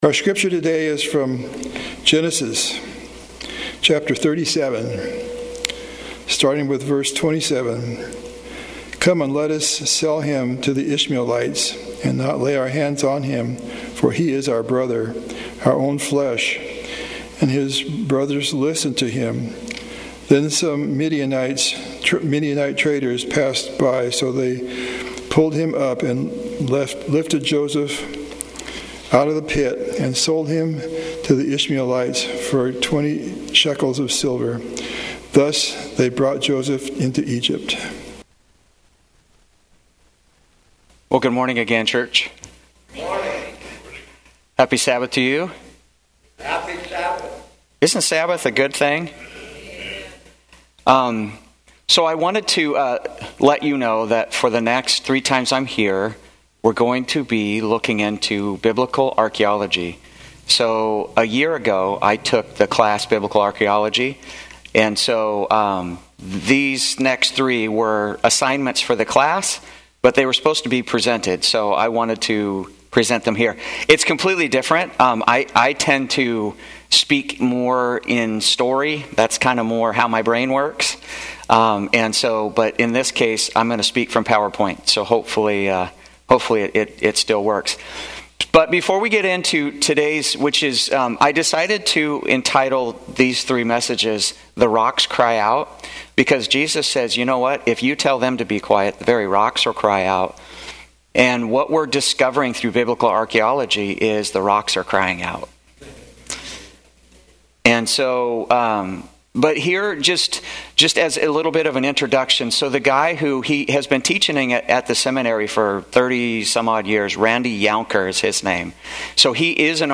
Sermons and Talks